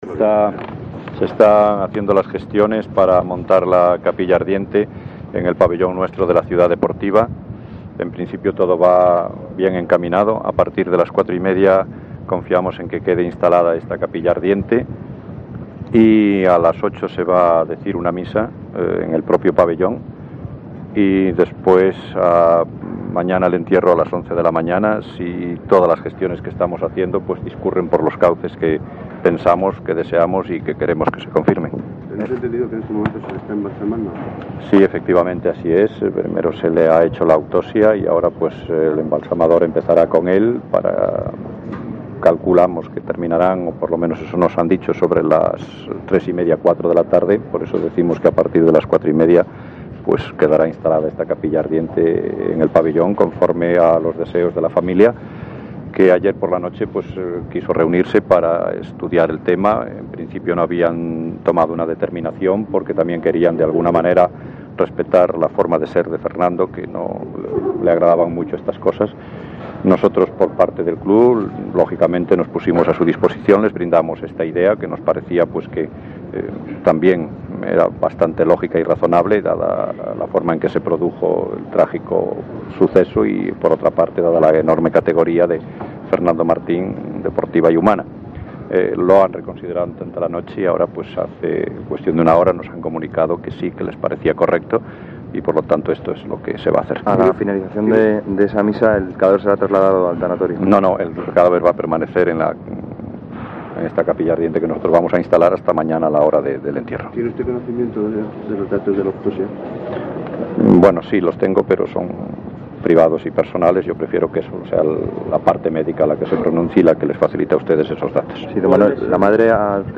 La rueda de prensa que ofreció el Real Madrid tras la muerte de Fernando Martín